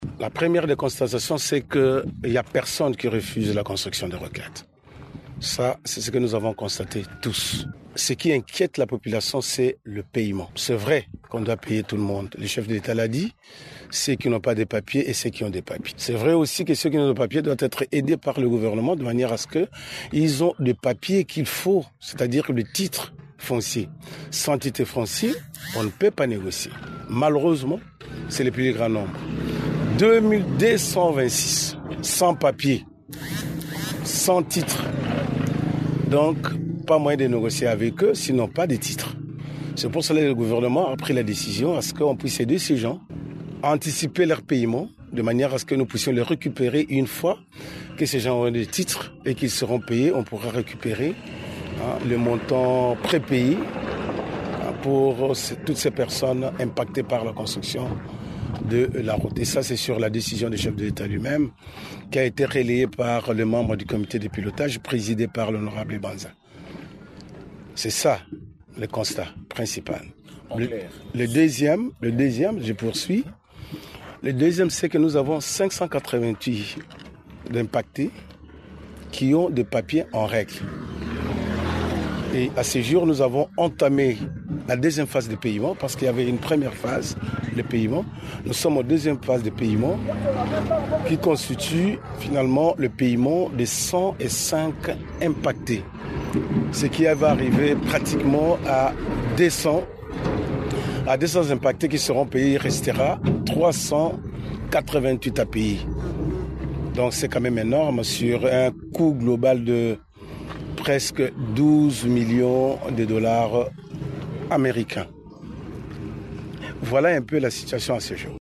Le secrétaire général aux Infrastructures et Travaux publics a livré ces chiffres samedi 22 novembre, lors d’une visite d’inspection sur la route des rocades.
Ecoutez les propos de Georges Koshi: